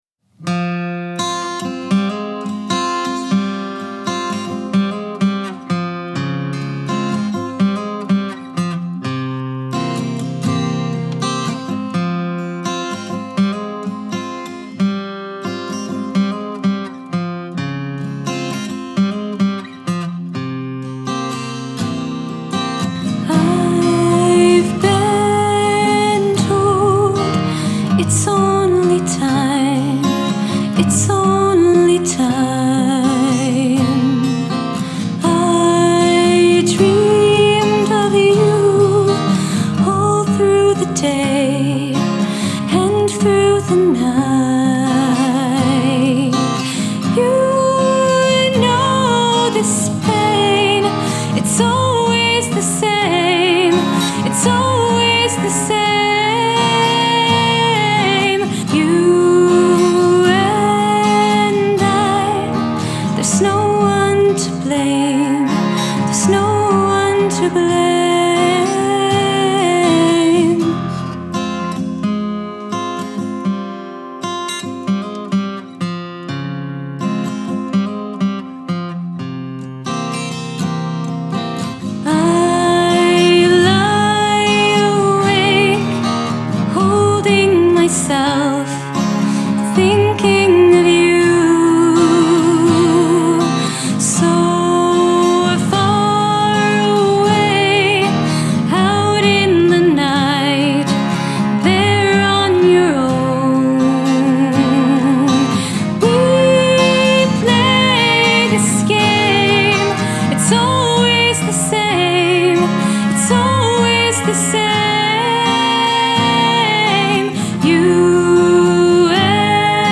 indie rock, folk, ballad
Cello